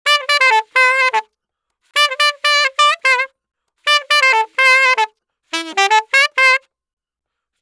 Saxofoon sample bij les 3:
saxophone-sample.mp3